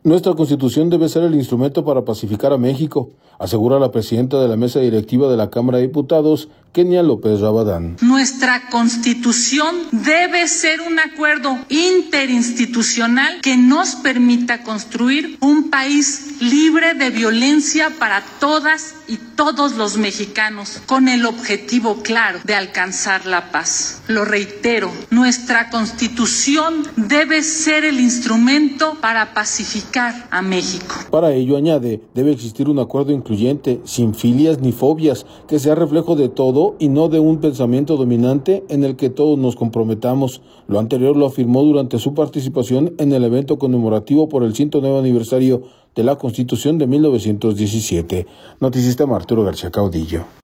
Nuestra Constitución debe ser el instrumento para pacificar a México, asegura la presidenta de la Mesa Directiva de la Cámara de Diputados, Kenia López Rabadán.
Para ello, añade, debe existir un acuerdo incluyente, sin filias ni fobias, que sea reflejo del todo y no de un pensamiento dominante en el que todos nos comprometamos. Lo anterior lo afirmó durante su participación en el evento conmemorativo por el 109 aniversario de la Constitución de 1917.